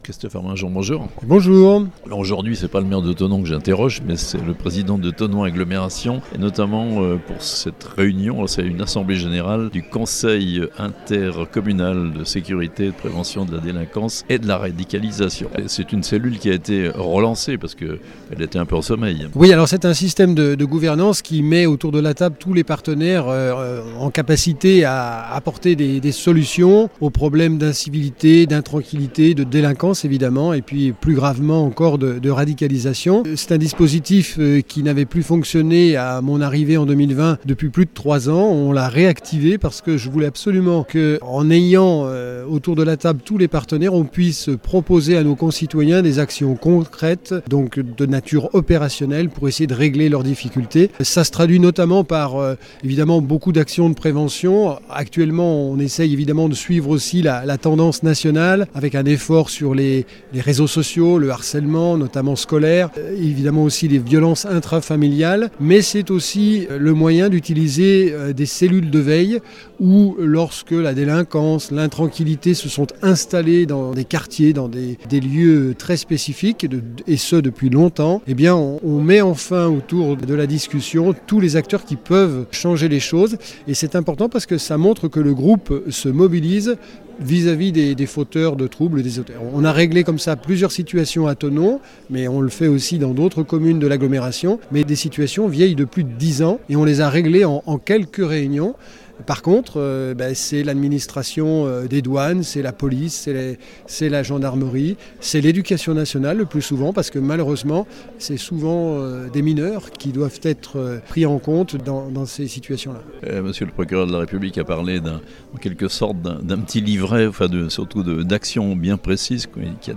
Le Conseil Intercommunal de Sécurité et de Prévention de la Délinquance et la Radicalisation relancé à Thonon Agglomération (interviews)